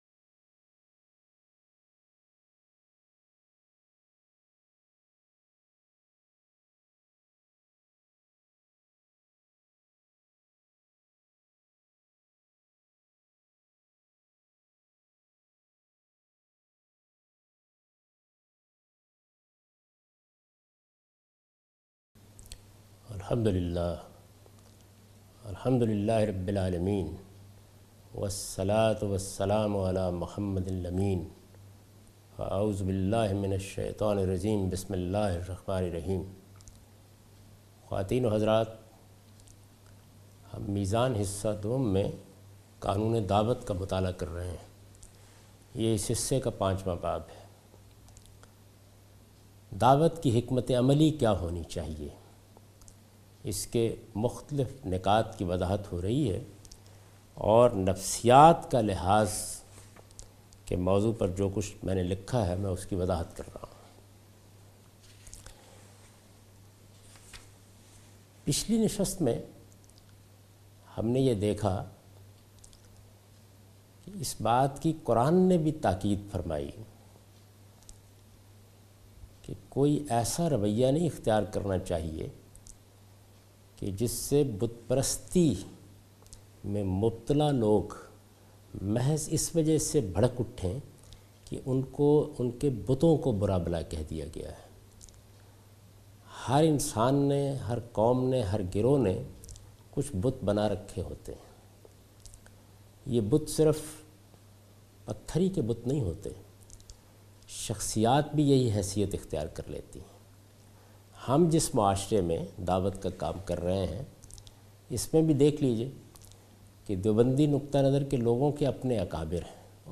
A comprehensive course taught by Javed Ahmed Ghamidi on his book Meezan. In this lecture series he will teach The Shari'ah of Preaching. This lecture contains 'Psychological Consideration' as a strategy of preaching.